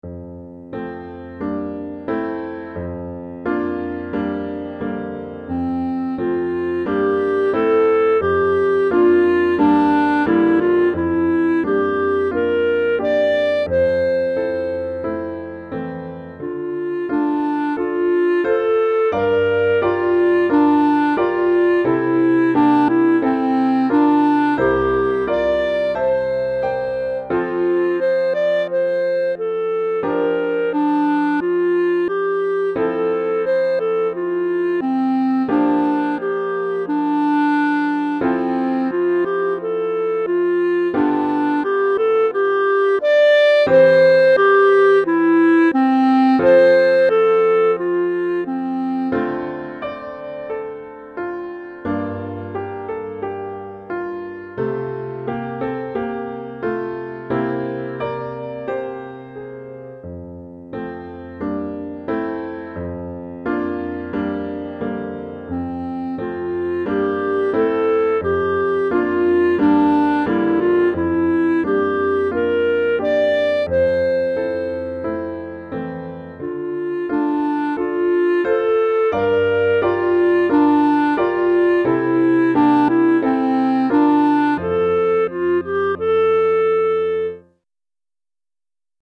Clarinette en Sib et Piano